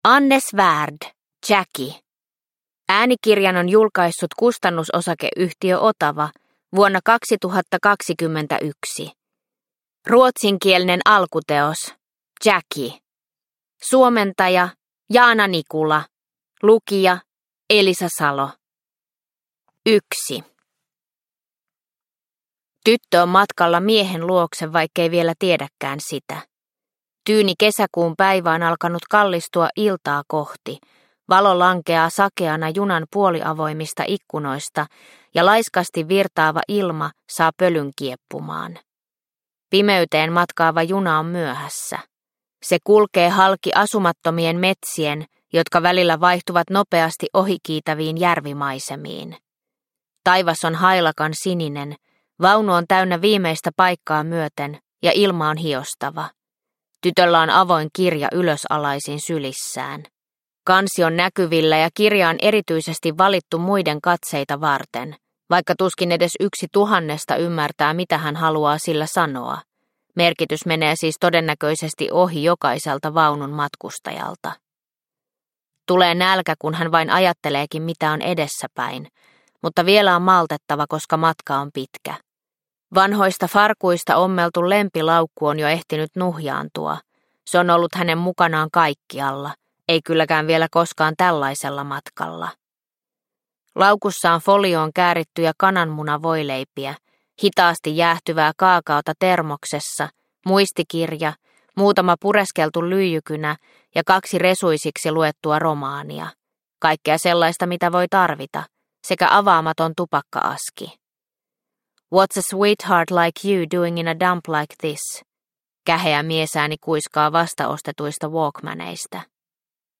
Jackie – Ljudbok – Laddas ner